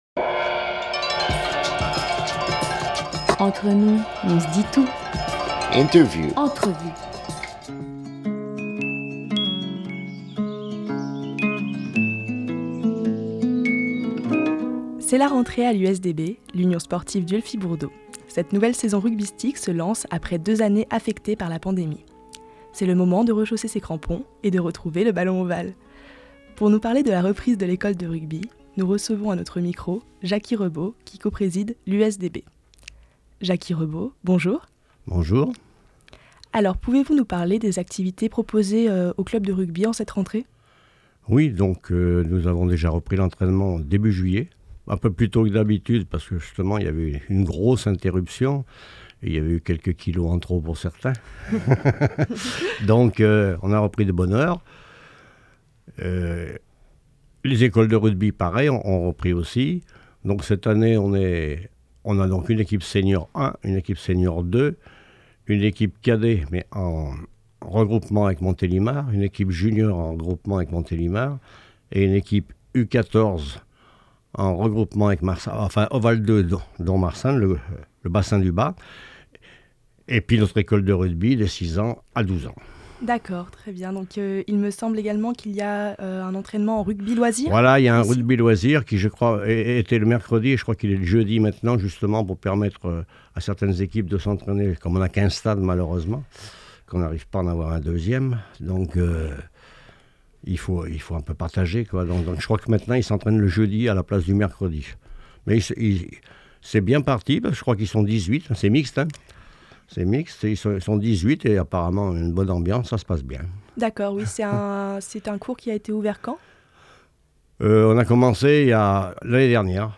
18 octobre 2021 10:45 | Interview